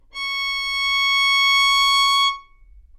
小提琴单音 " 小提琴 Csharp6
描述：在巴塞罗那Universitat Pompeu Fabra音乐技术集团的goodsounds.org项目的背景下录制。
Tag: 好声音 单音符 小提琴 多重采样 纽曼-U87 Csharp6